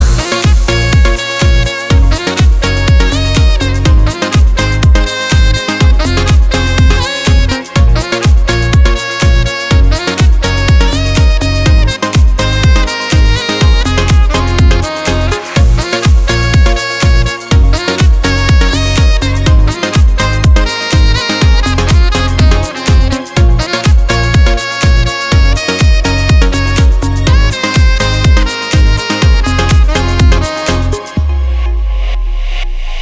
• Качество: 320, Stereo
инструментальные
Саксофон